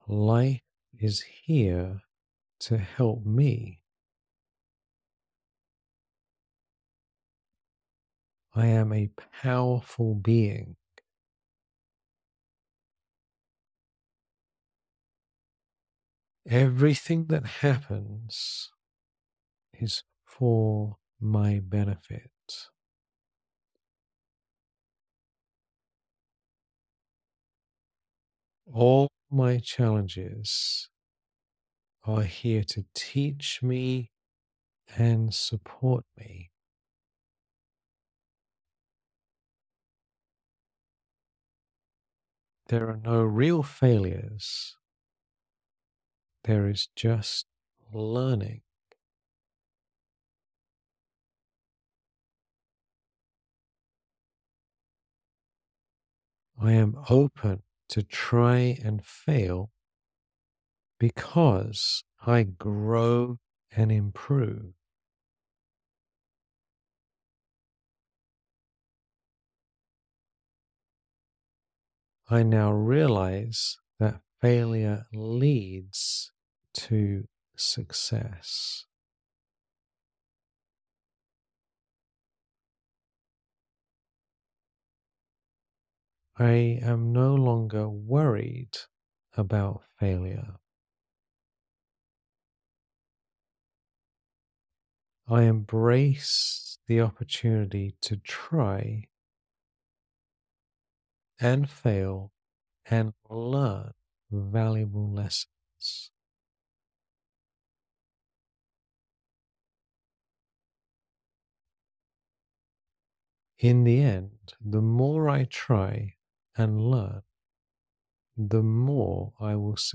[Meditation] Overcome Fear of Failure